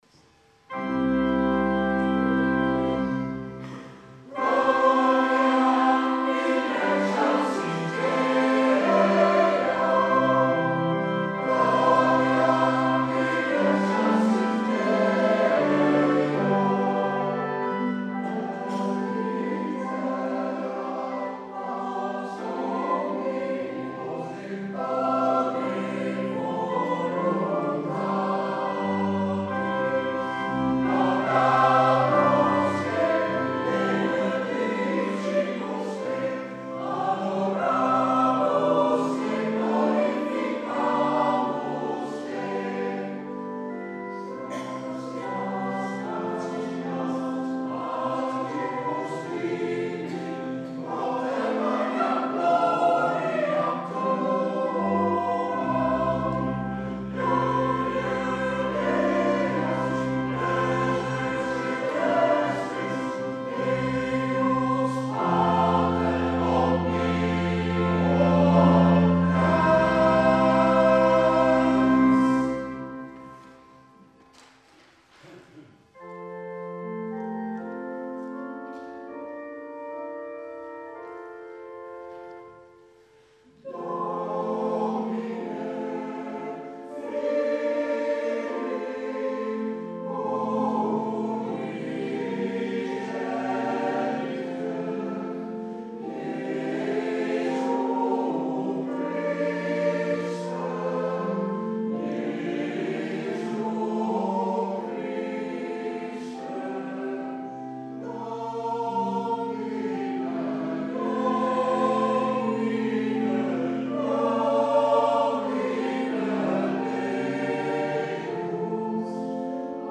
Paaswake 2016